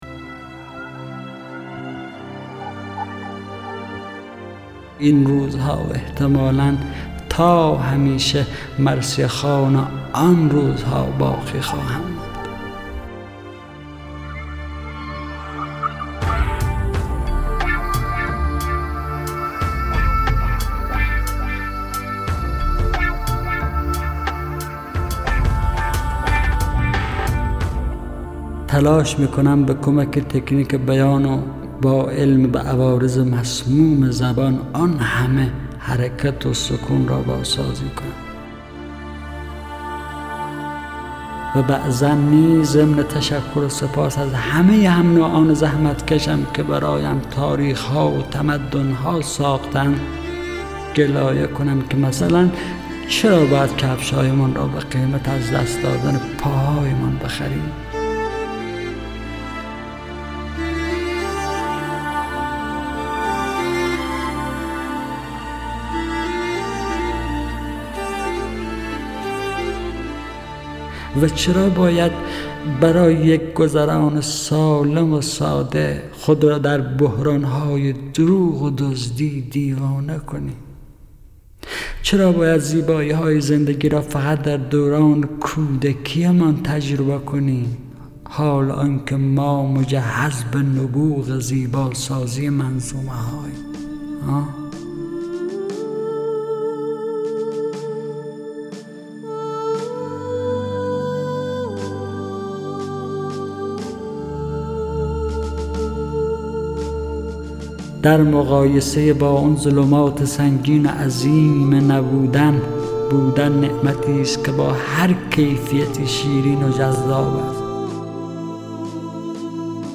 دانلود دکلمه این روزها حسین پناهی
گوینده :   [حسین پناهی]